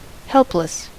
Ääntäminen
US : IPA : [ˈhɛlp.lɪs]